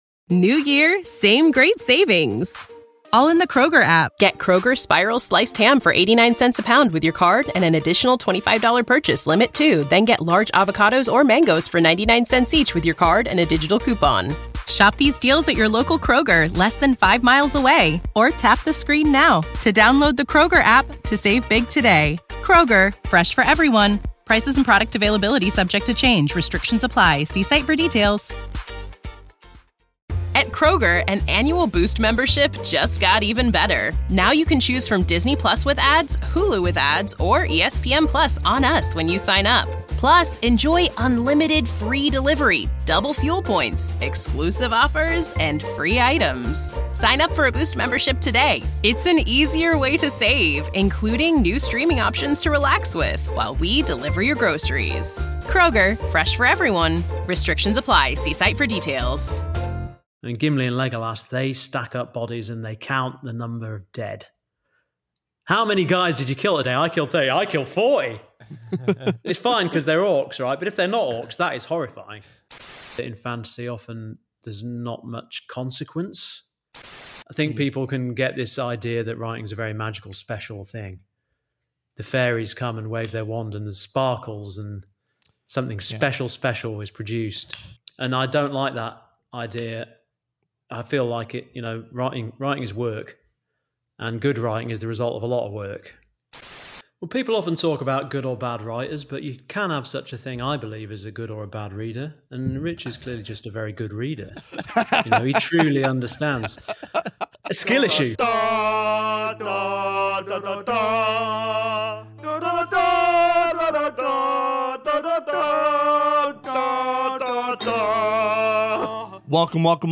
2 guys talking about books (mostly fantasy & sci-fi).